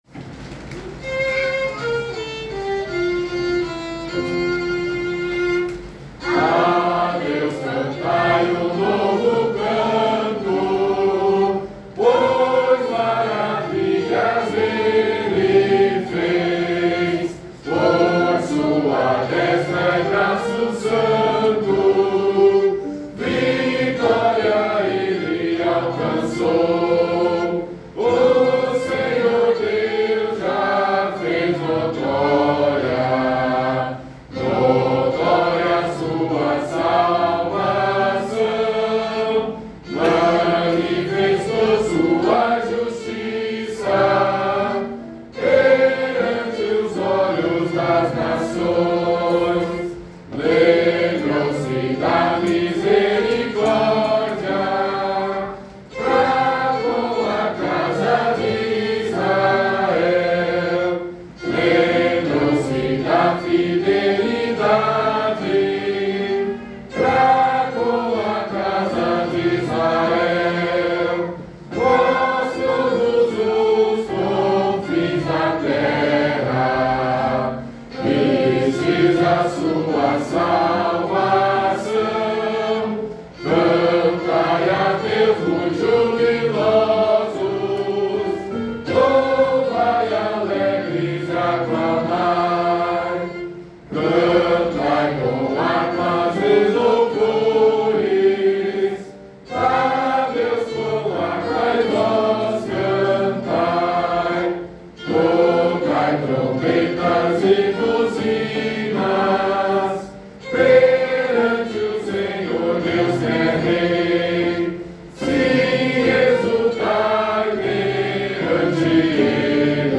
Modo: hipojônio
Harmonização: Claude Goudimel, 1564
salmo_98A_cantado.mp3